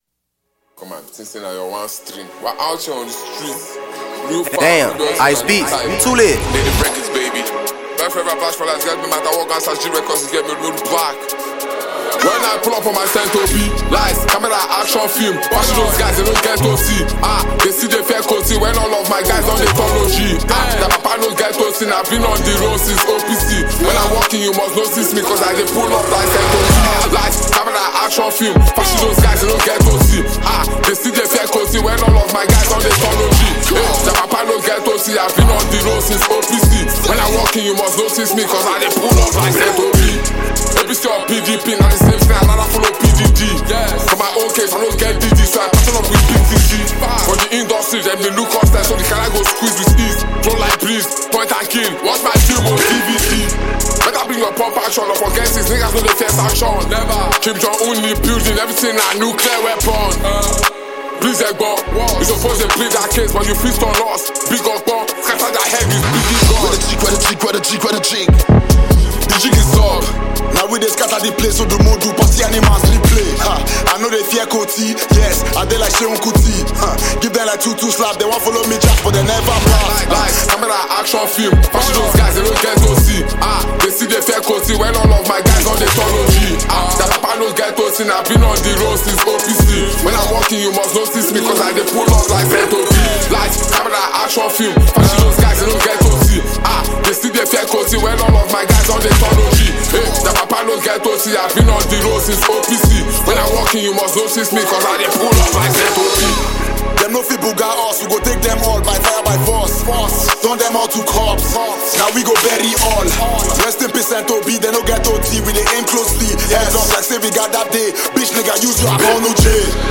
Phenomenon talented Nigerian rap artist and performer
new energizing song